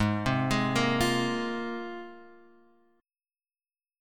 G#6add9 chord